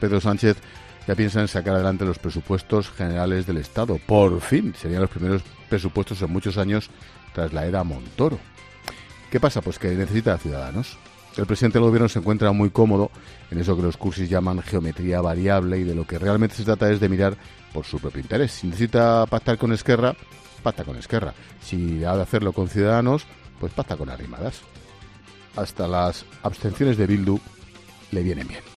El presentador de 'La Linterna' ha vuelto a poner en entredicho los acuerdos que busca el Gobierno
Ángel Expósito ha aprovechado uno de sus monólogos de 'La Linterna' de este martes para 'celebrar' que Pedro Sánchez ya piensa en llegar a acuerdos para sacar adelante unos nuevos Presupuestos Generales del Estado.